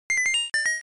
Sound effect from Super Mario Land 2: 6 Golden Coins
SML2_Pause.oga.mp3